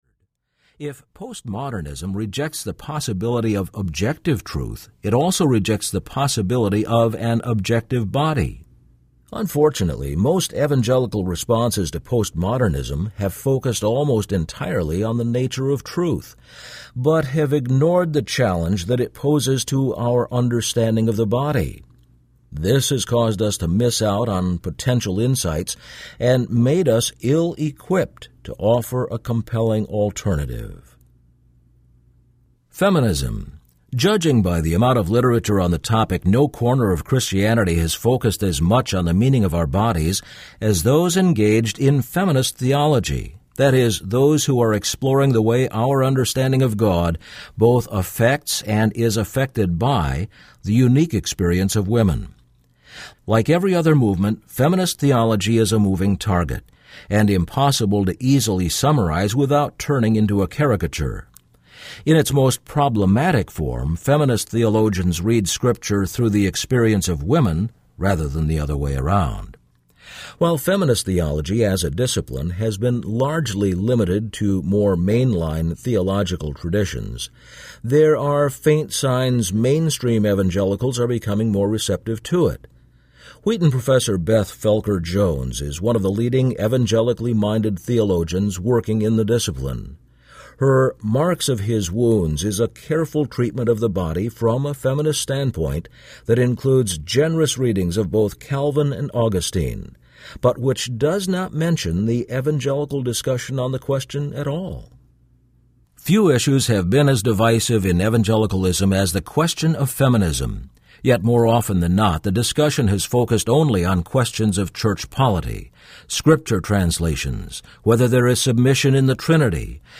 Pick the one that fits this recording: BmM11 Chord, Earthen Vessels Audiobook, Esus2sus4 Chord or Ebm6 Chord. Earthen Vessels Audiobook